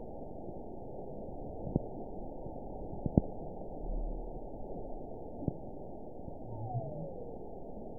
event 921866 date 12/20/24 time 07:46:53 GMT (6 months ago) score 9.43 location TSS-AB04 detected by nrw target species NRW annotations +NRW Spectrogram: Frequency (kHz) vs. Time (s) audio not available .wav